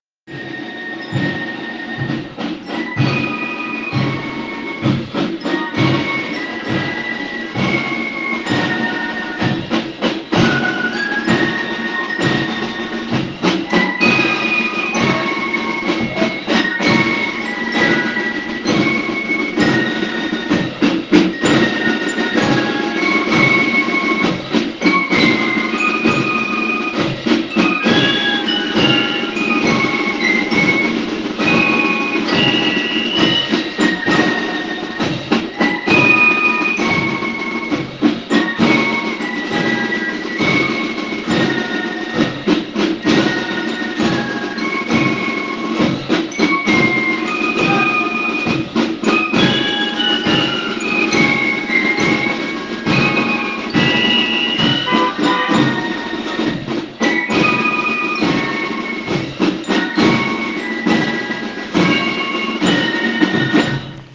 Maiwecken
Maiwecken2019.ogg